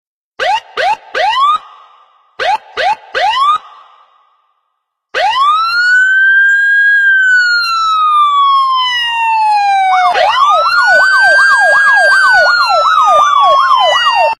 P O L I C E
policeiskie-sireny_24622.mp3